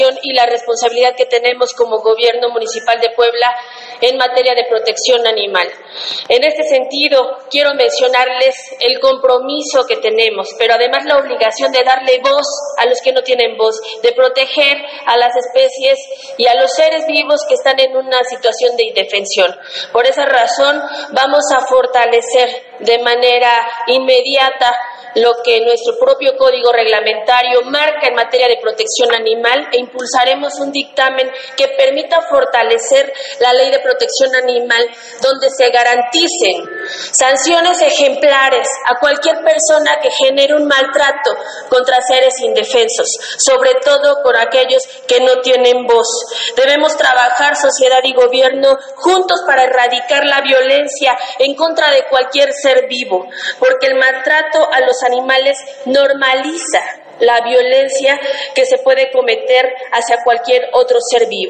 En conferencia de presa en Palacio Municipal